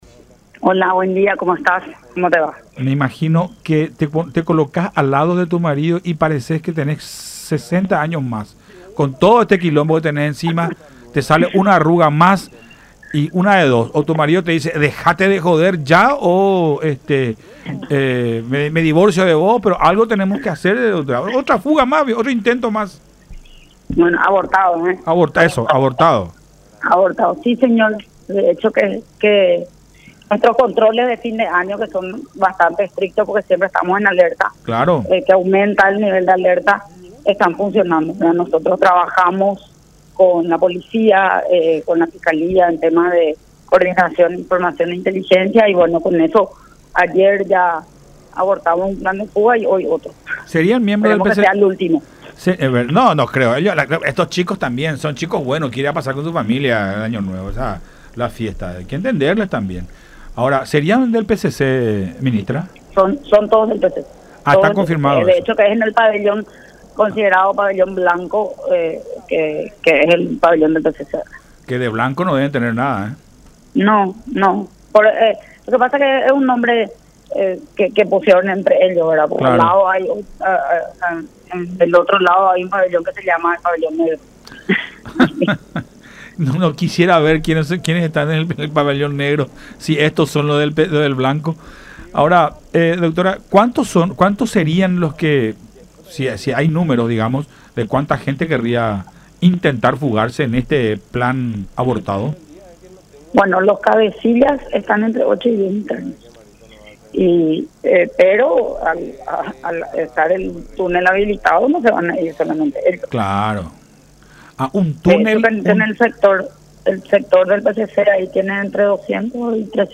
“Nuestros controles de fin de año son bastante estrictos y están dando resultados. Esto se dio en el Pabellón blanco, que es justamente del PCC”, afirmó la ministra de Justicia, Cecilia Pérez, en conversación con Enfoque 800 por La Unión.